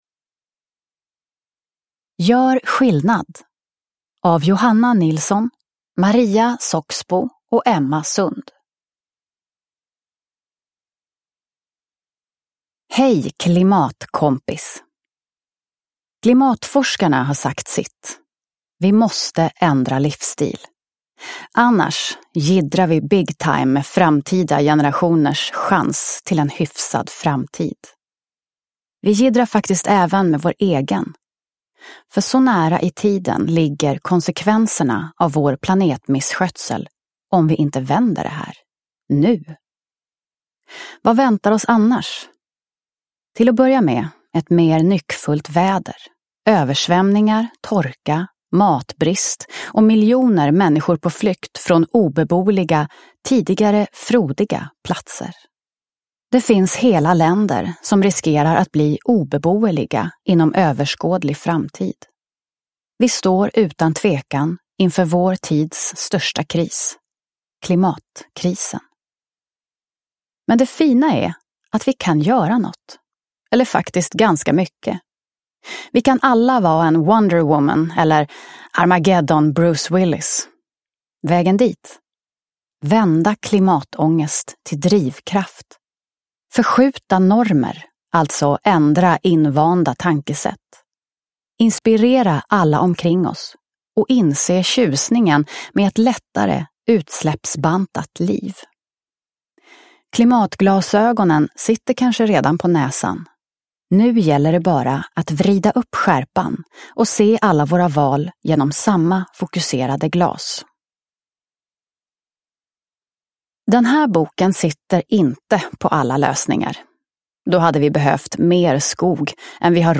Gör skillnad! : från klimatångest till handlingskraft – Ljudbok – Laddas ner